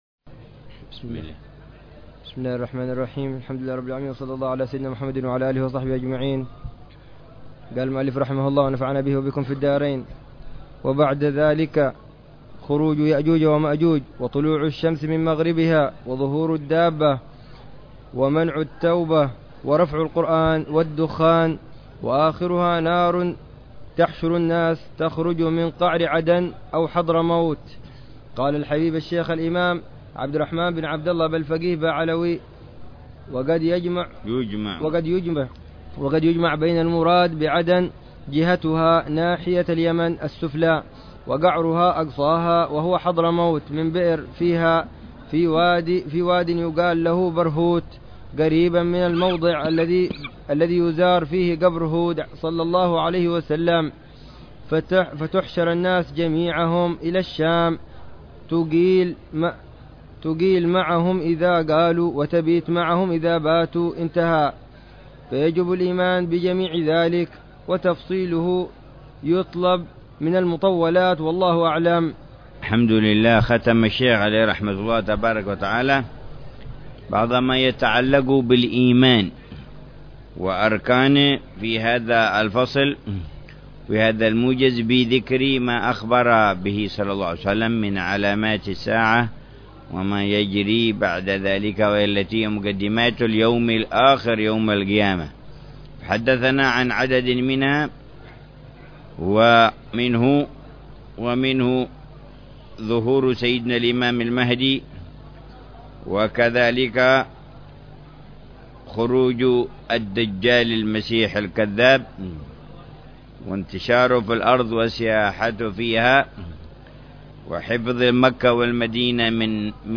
شرح